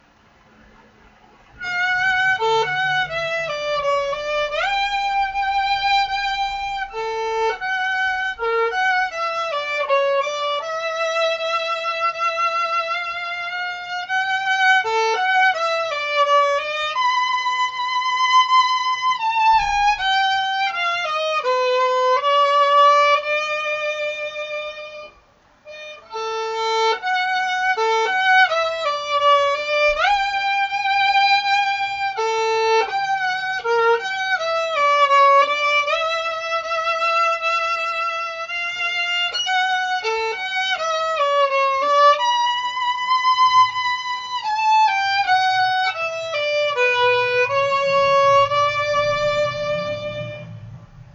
В реале оно получше звучит, микрофор говно.
Струнам и смычку уже два года, не струны, а куски проволоки уже, без шуток.
Не так уж плохо вышло, кстати, местами слышны косяки, но все равно очень слушабельно и здорово.